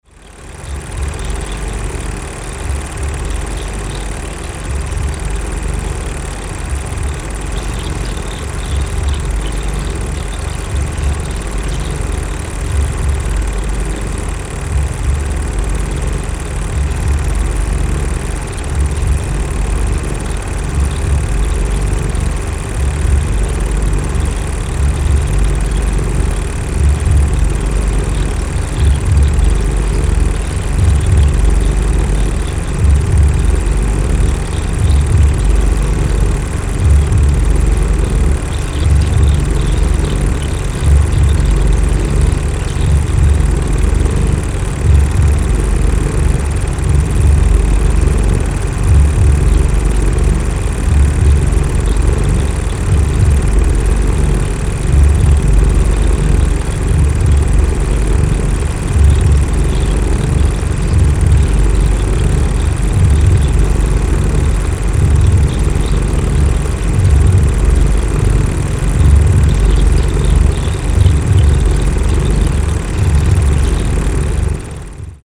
Soundtrack excerpt